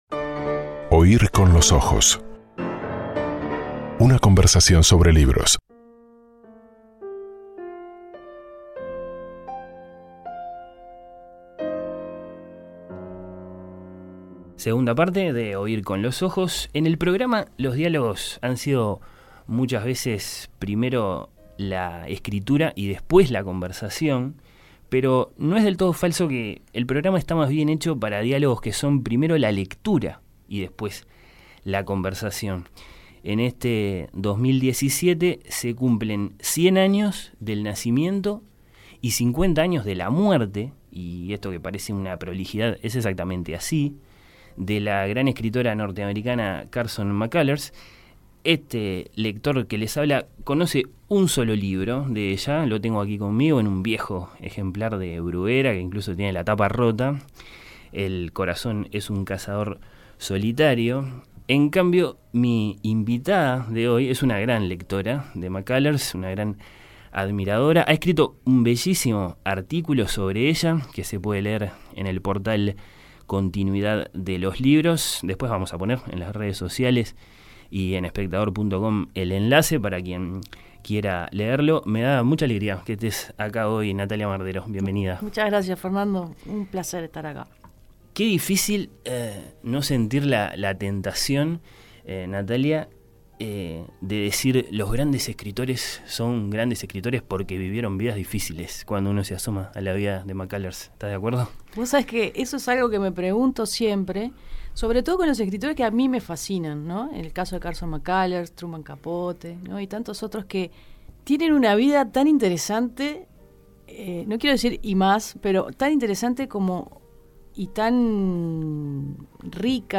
El diálogo